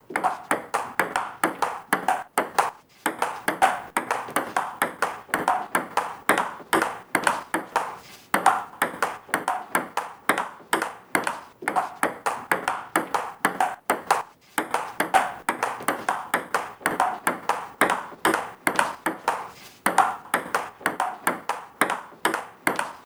Jugada de ping-pong
Sonidos: Acciones humanas
Sonidos: Deportes